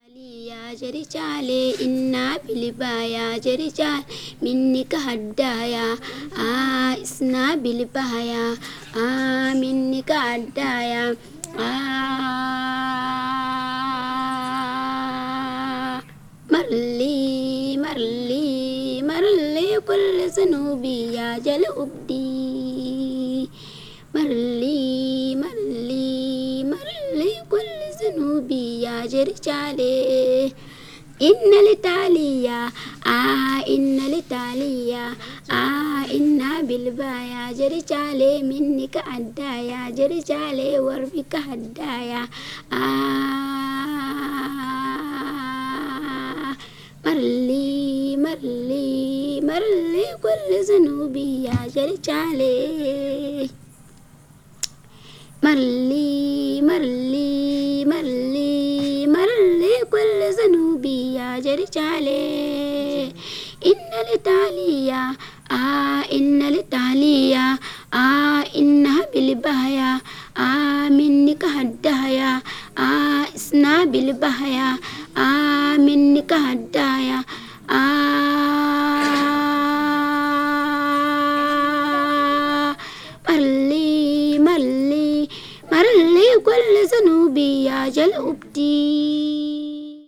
ethnic music   field recording   ghana   primitive   traditional   west africa